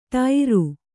♪ ṭairu